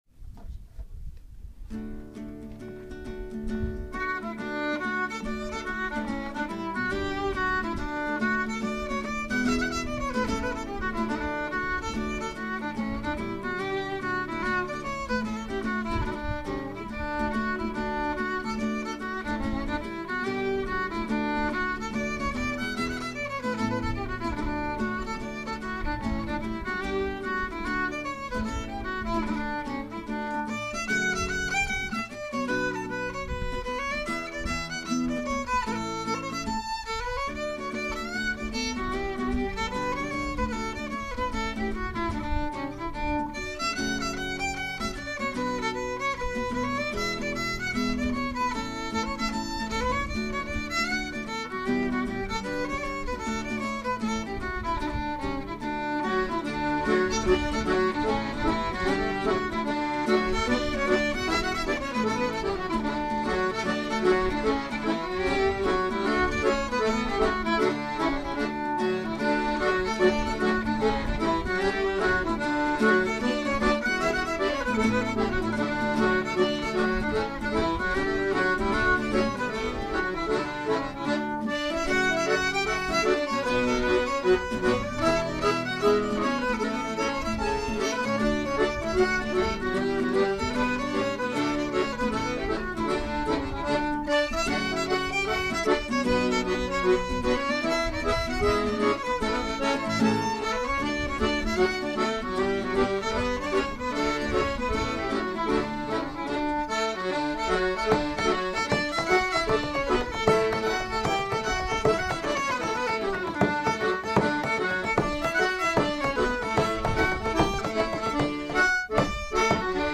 This is a flute and whistle-friendly version of the Galway Hornpipe, which is also known as "The Baldheaded Bachelor" for some reason.